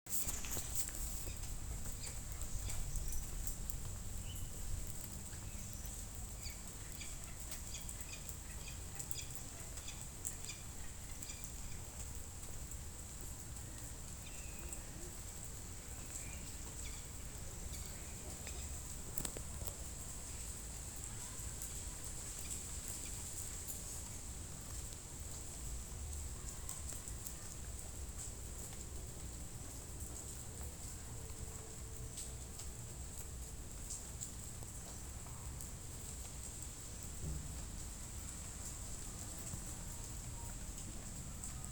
Peregrine Falcon (Falco peregrinus)
Location or protected area: San Miguel de Tucumán
Condition: Wild
Certainty: Recorded vocal
Halcon-peregrino.mp3